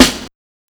Snares
SNARE ATTACK 2.wav